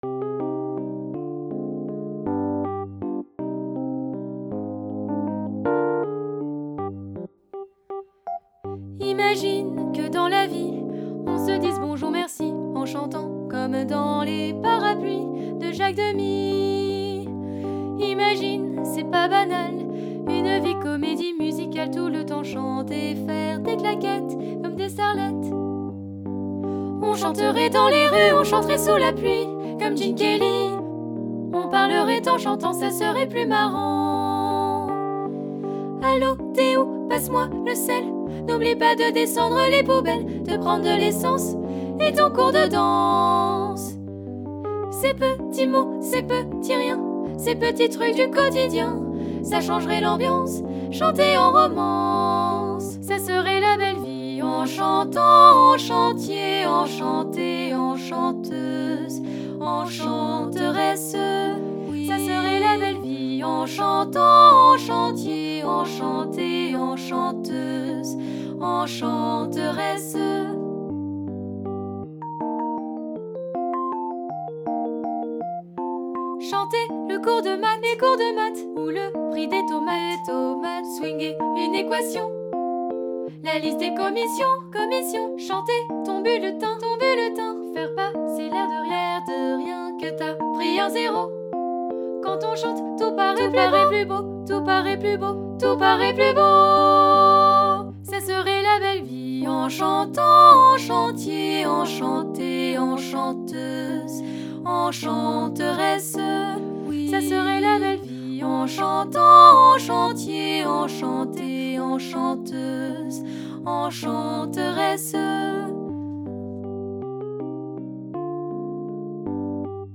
Epoque :  Musique d'aujourd'hui
Genre :  Chanson
Style :  Avec accompagnement
Effectif :  PolyphonieVoix égales
Enregistrement piano et voix